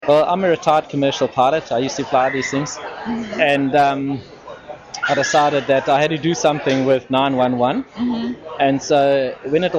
South African Male
• Male, approx. 40 years old
• From the Johannesburg region
South-African-English.mp3